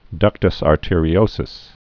(dŭktəs är-tîrē-ōsəs)